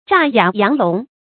诈哑佯聋 zhà yǎ yáng lóng 成语解释 假装哑巴聋子。